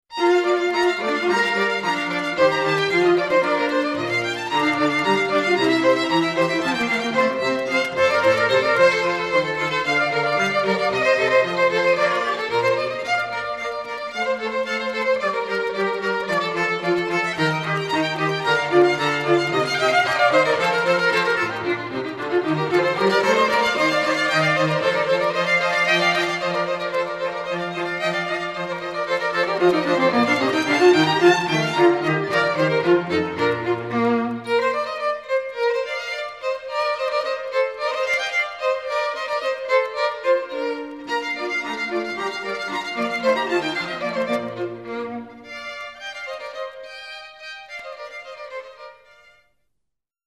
Click the blue titles below to hear Cotswold Ensemble string quartet players performing.